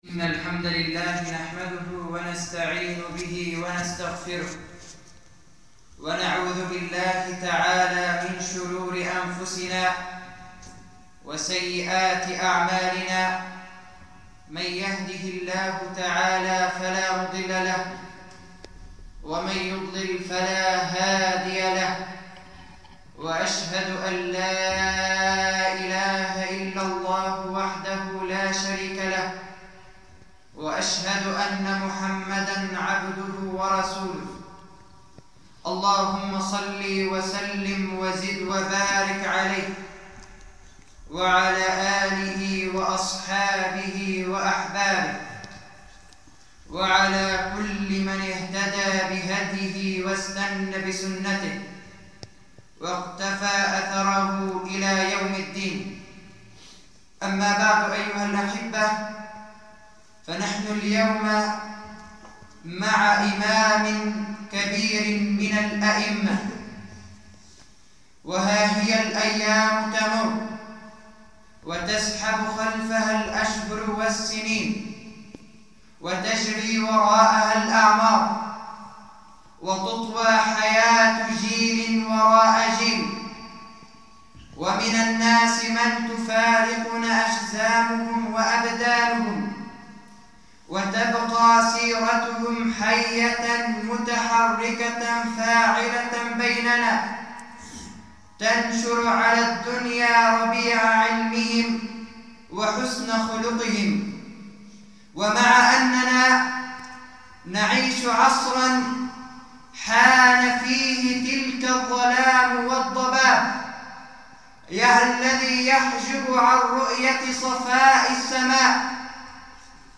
[خطبة جمعة] الإمام الشافعي رحمه الله
المكان: مسجد إيزال-الضنية الموضوع: الإمام الشافعي رحمه الله تحميل